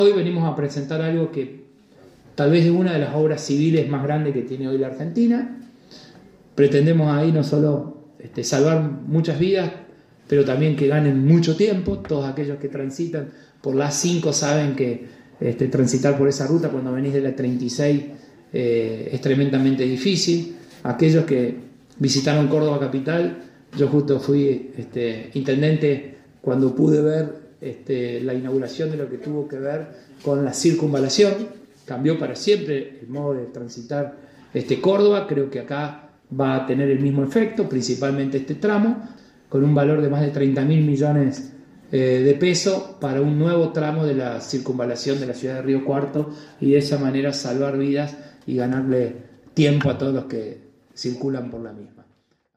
Audio: Martín Llaryora.